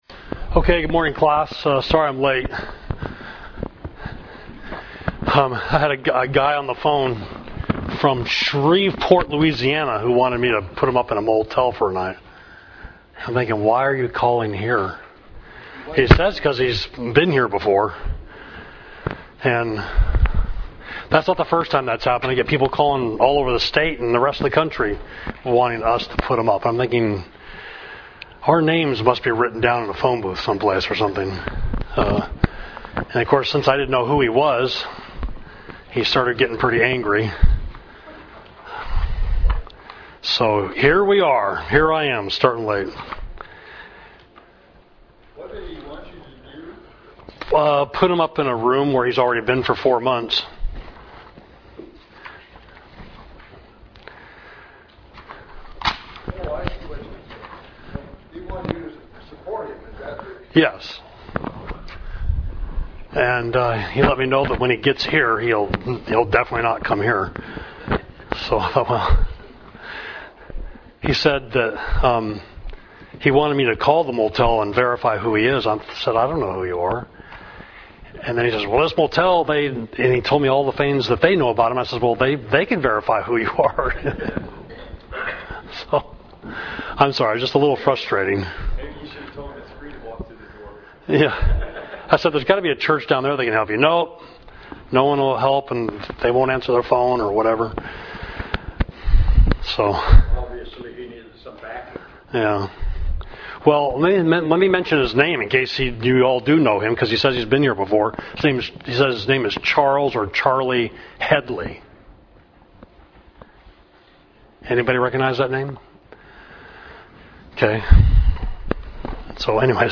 June 10, 2018 – Class: Outline of Isaiah